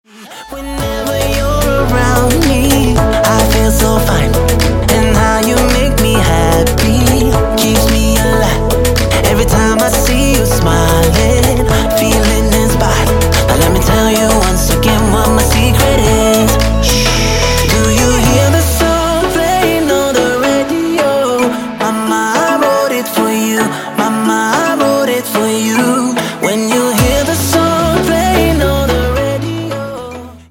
• Качество: 128, Stereo
поп
мужской вокал
tropical dance